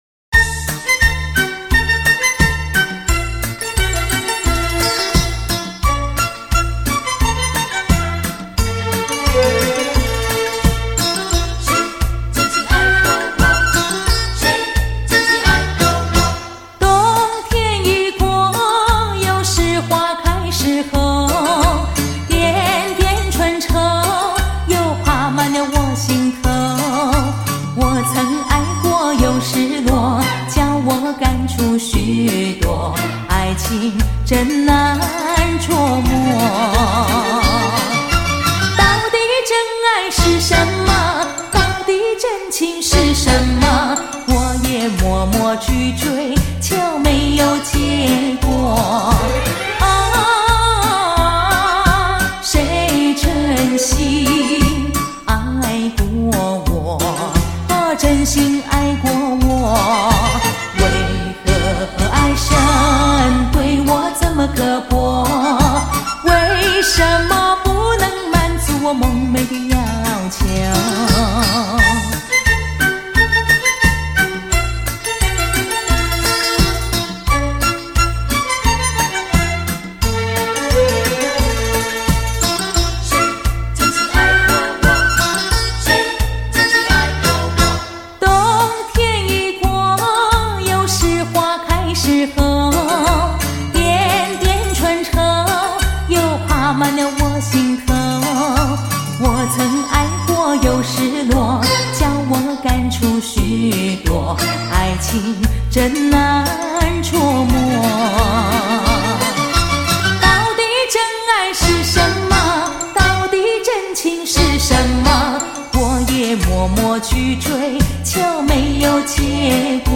经典婉转好恋歌·真情不朽永传唱